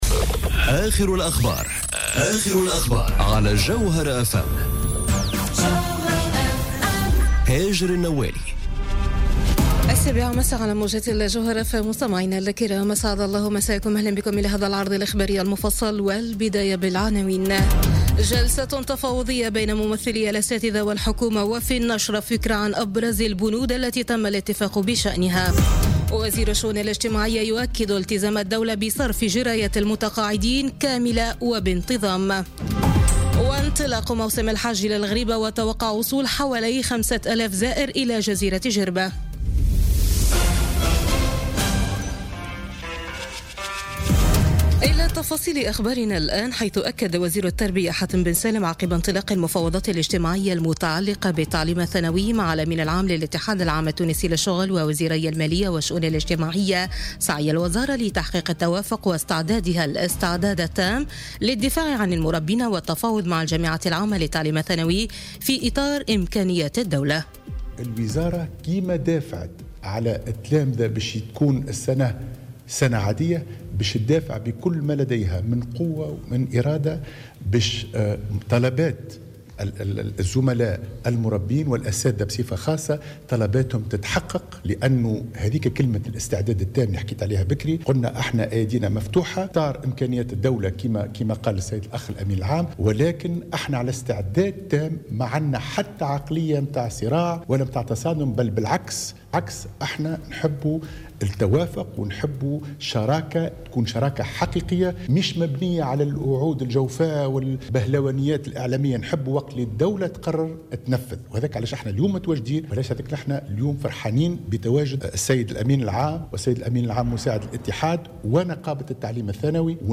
نشرة أخبار السابعة مساء ليوم الاثنين 30 أفريل 2018